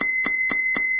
timerClickShort.wav